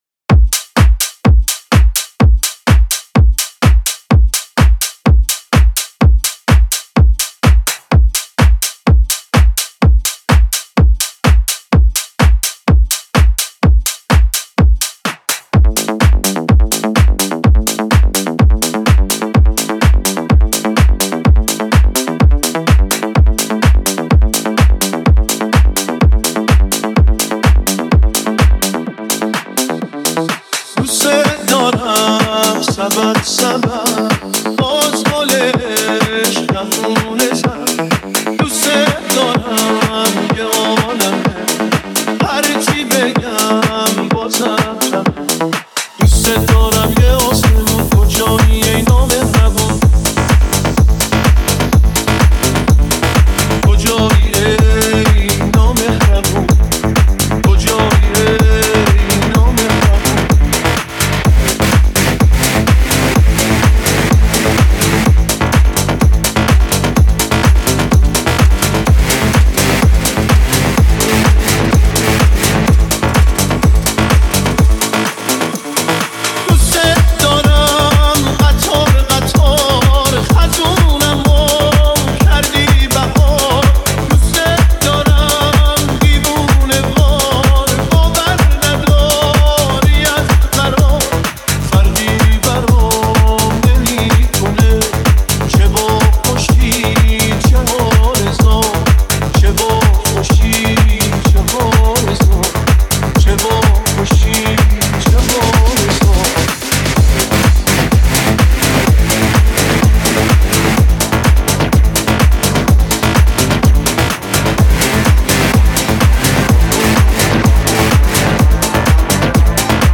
756 بازدید ۱۲ بهمن ۱۴۰۲ ریمیکس , ریمیکس فارسی